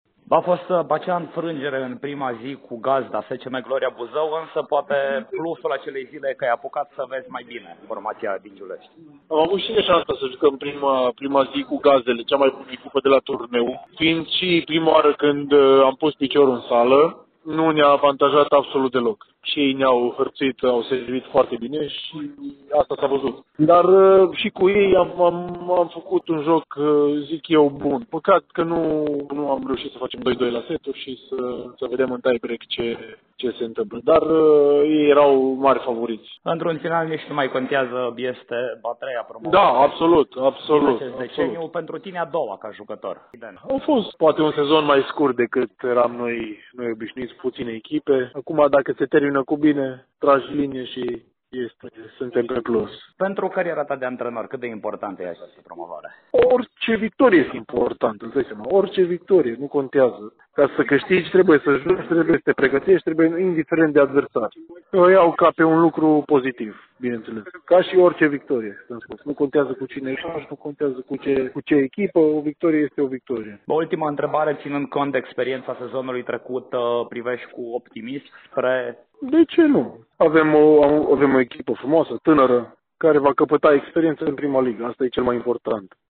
despre turneul de promovare de la Buzău și despre perspectivele „studenților”, într-un dialog